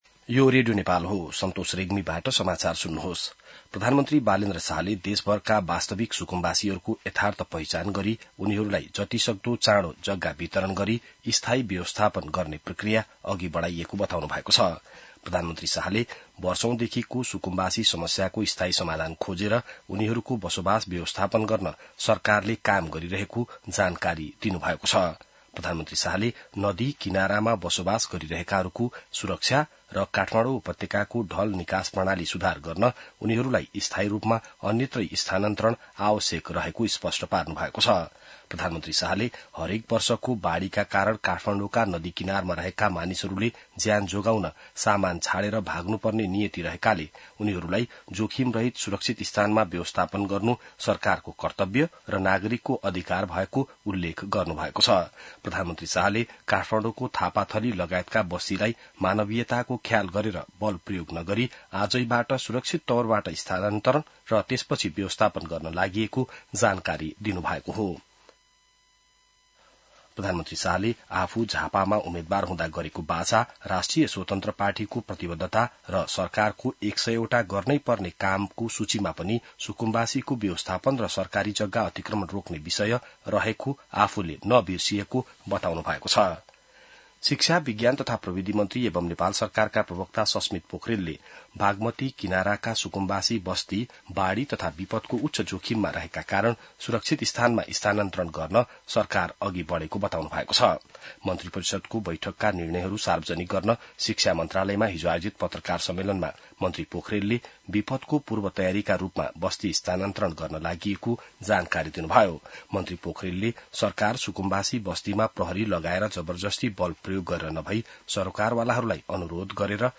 An online outlet of Nepal's national radio broadcaster
बिहान ६ बजेको नेपाली समाचार : १२ वैशाख , २०८३